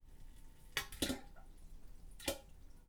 Toilet Sound Effects
Plop 00:03